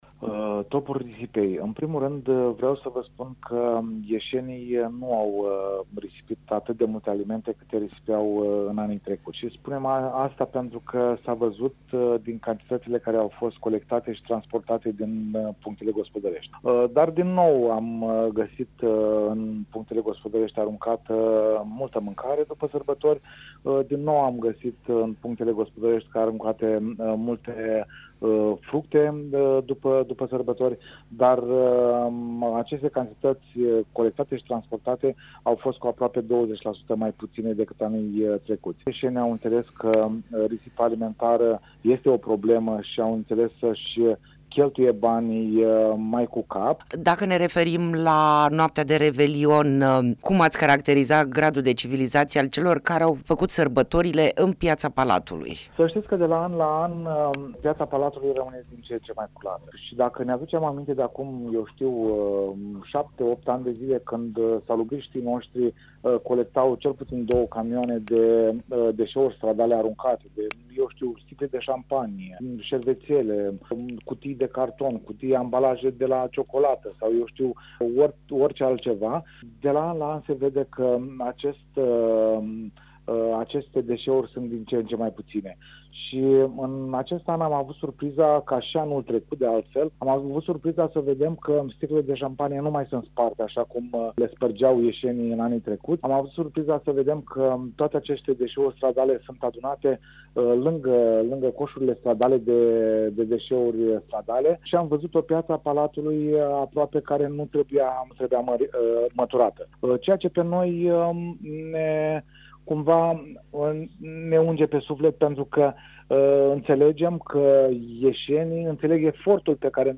Interviu-risipa-alimentara-si-brazi.mp3